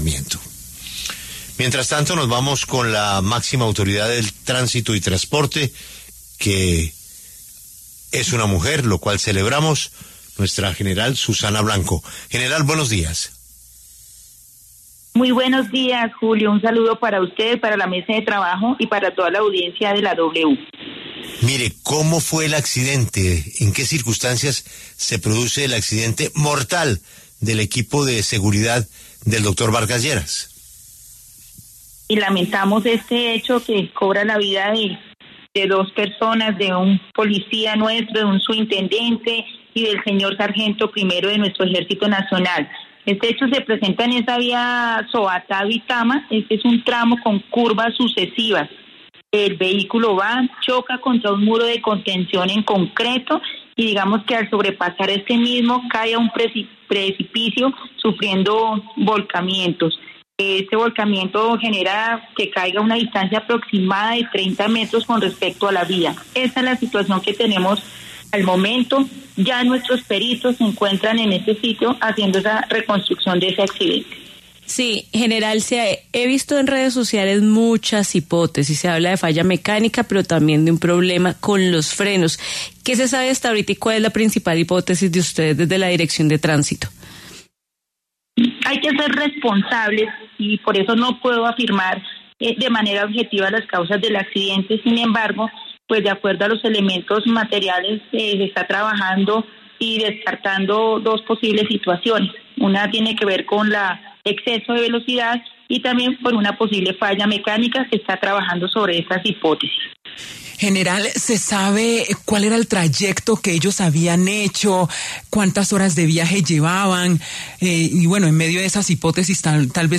La brigadier general Susana Blanco, directora Tránsito y Transporte Policía Nacional, se pronunció en La W tras el accidente de esquema de seguridad de Germán Vargas Lleras.